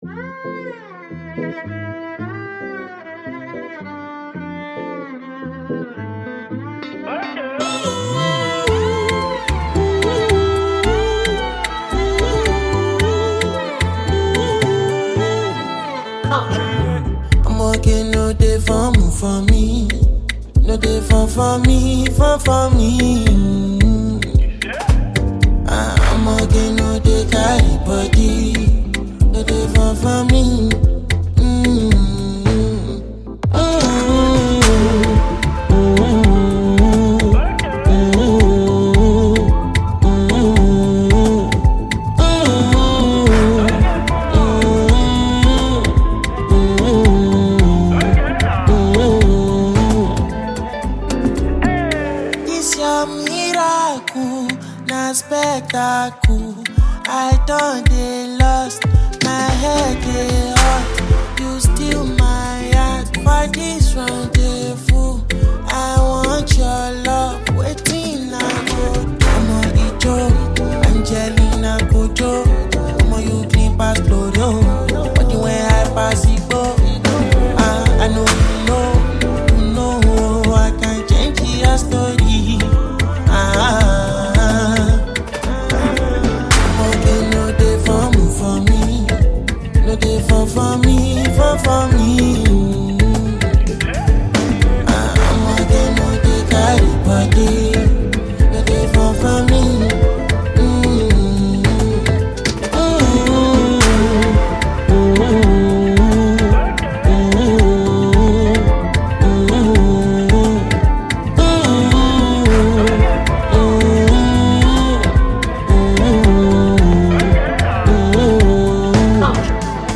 Nigerian Afro-Pop singer
Known for his kind of uptempo record